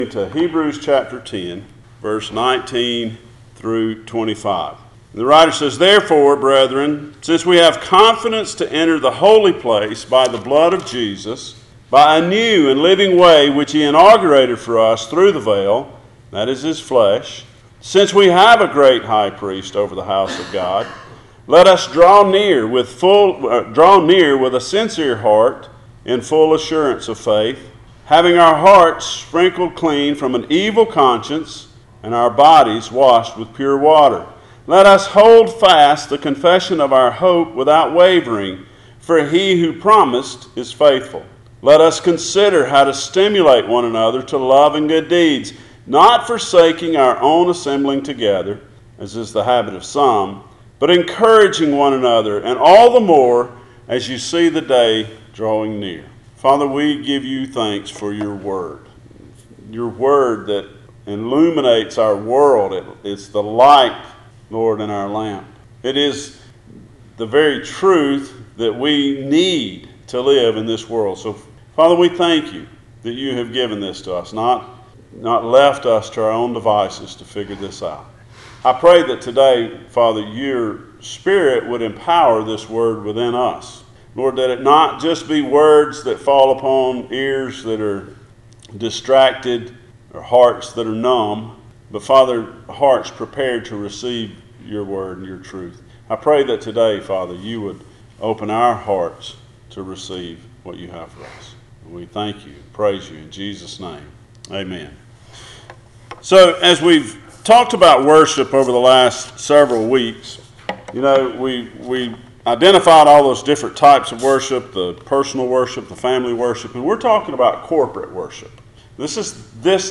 Passage: Hebrews 10:19-25 Service Type: Sunday Morning